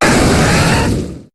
Cri de Galopa dans Pokémon HOME.